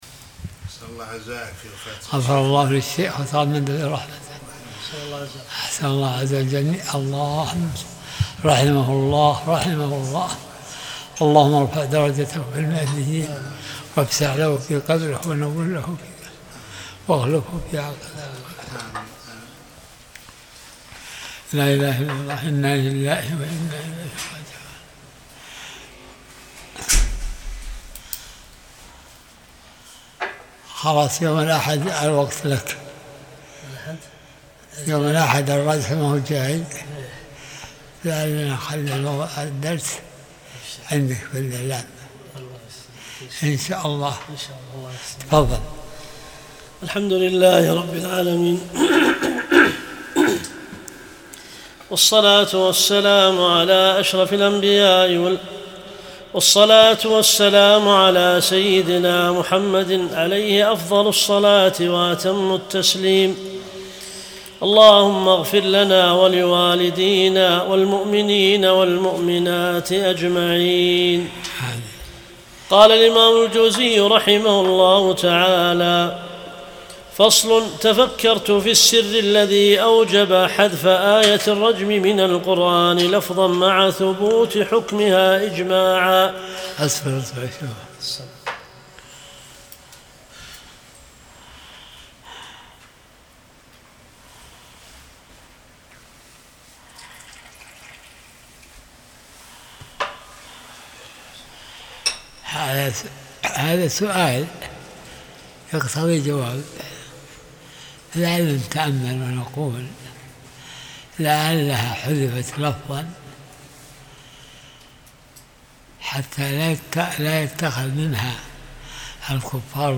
درس الأربعاء 42